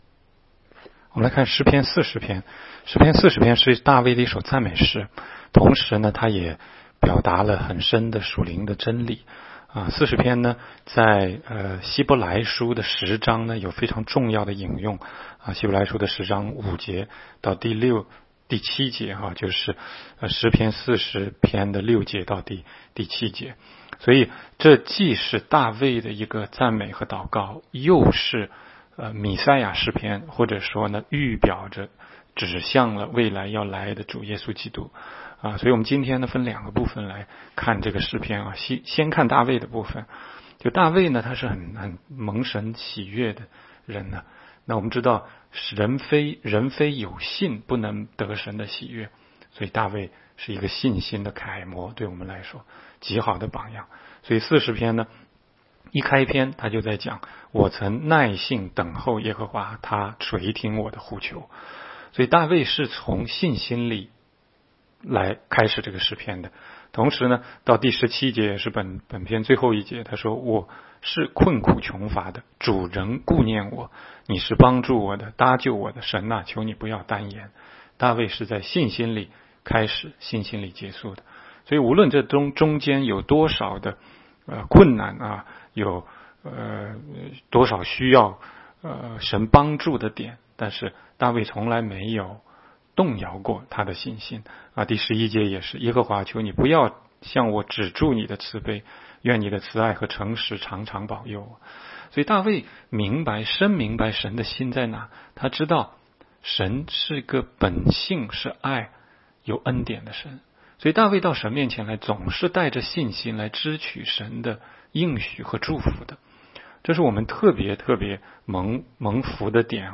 16街讲道录音 - 每日读经-《诗篇》40章
每日读经